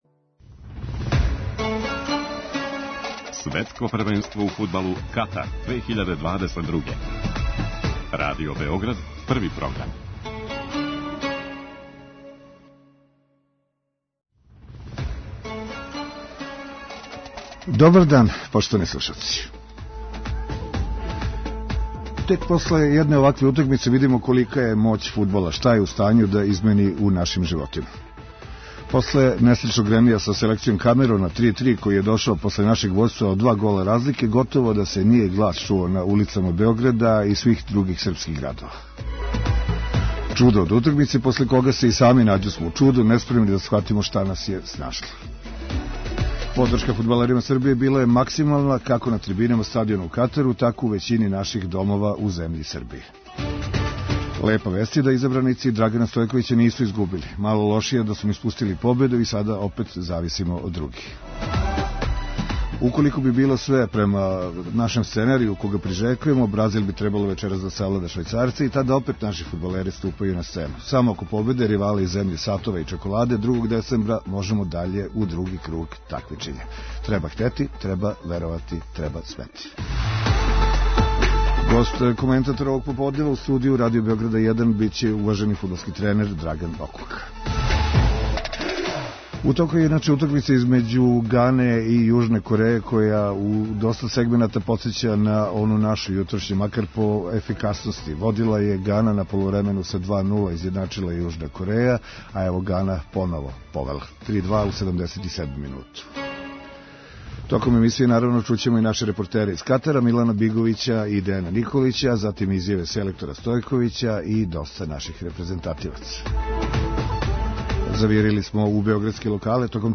Гост - коментатор овог поподнева у студију Радио Београда 1